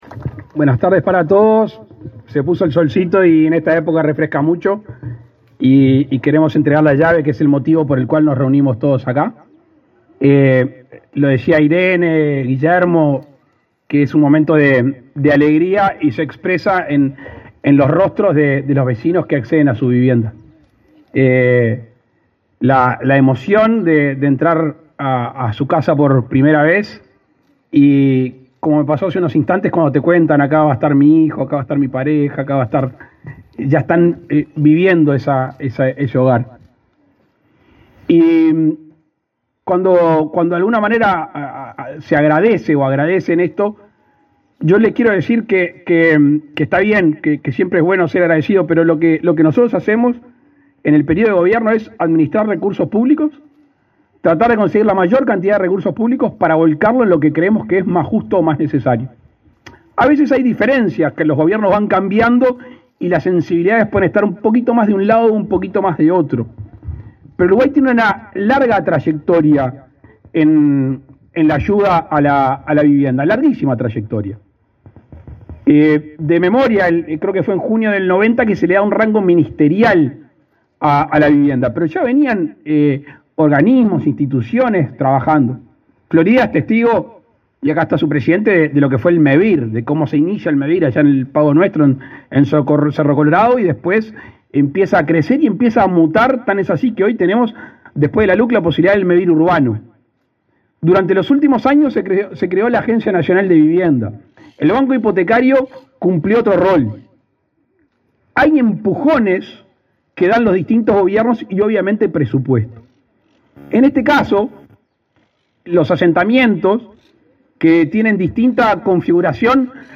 Palabras del presidente de la República, Luis Lacalle Pou
Con la presencia del presidente de la República, Luis Lacalle Pou, Mevir entregó viviendas en el departamento de Florida, este 2 de mayo.